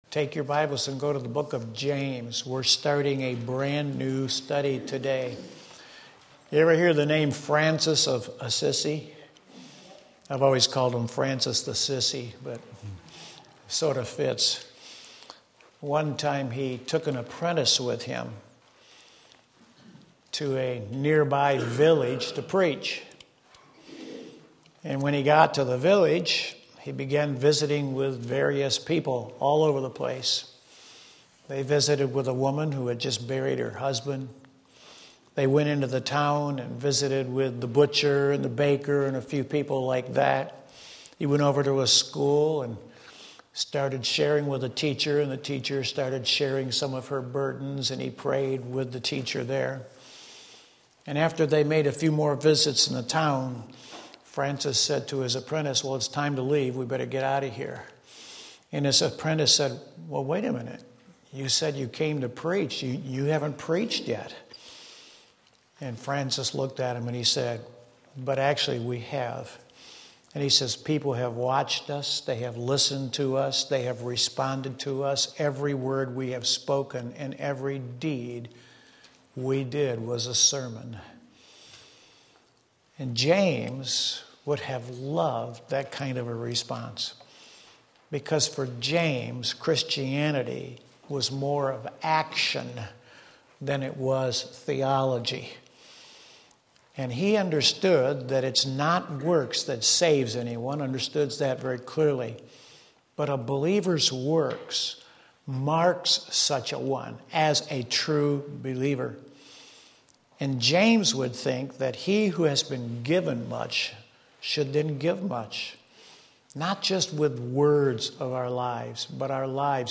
Introduction to James James 1:1 Sunday School